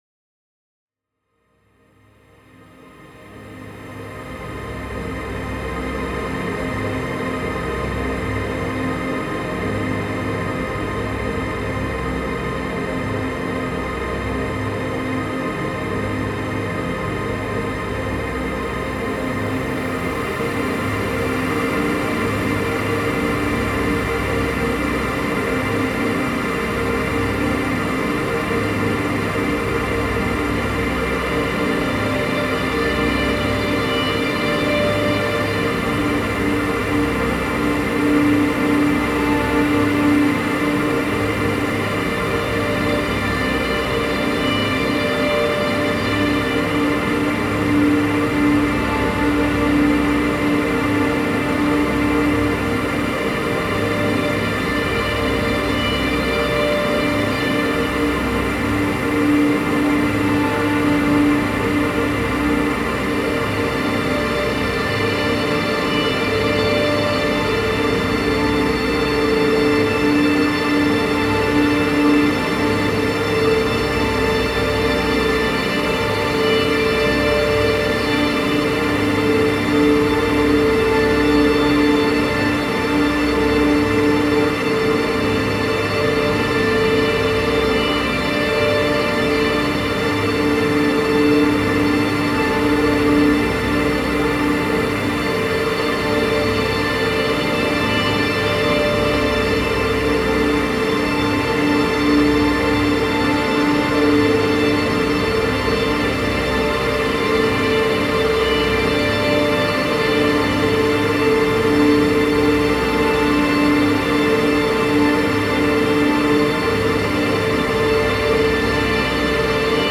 Wind races through giant structures.